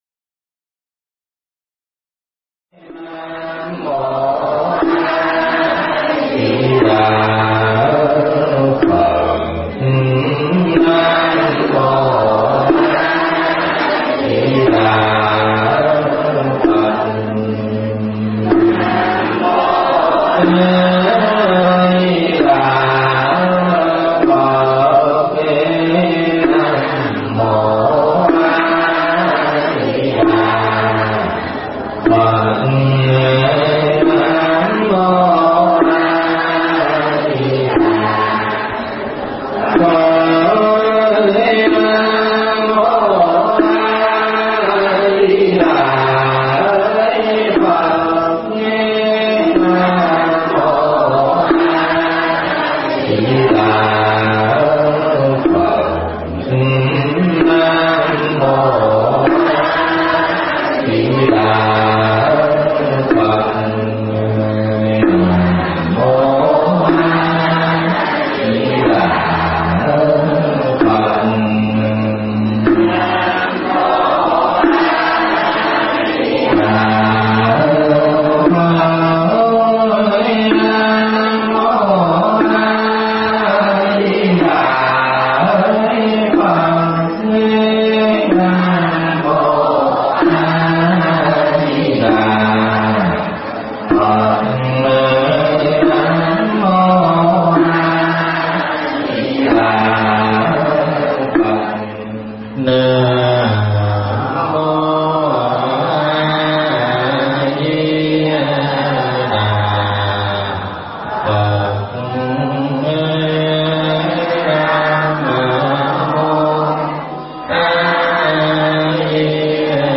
Mp3 Pháp Thoại Cúng Dường Ngày Vía Phật Phần 1
giảng tại chùa Phước Linh